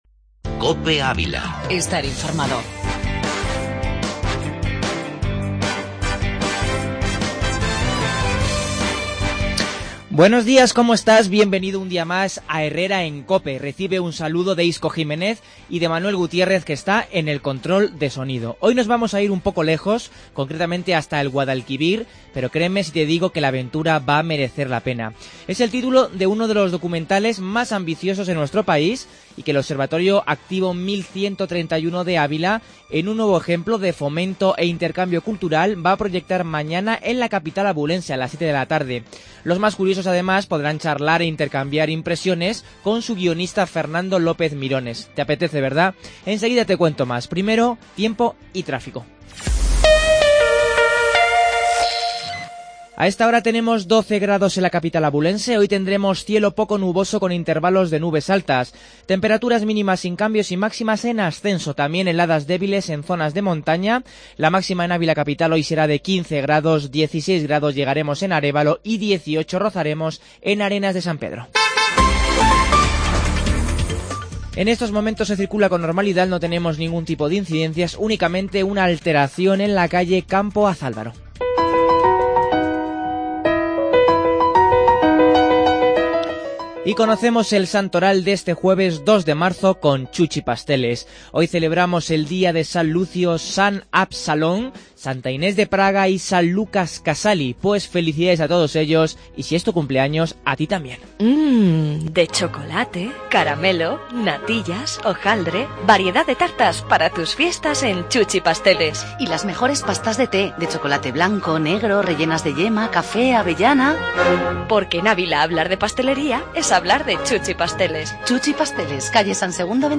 AUDIO: Entrevista documental "Guadalquivir".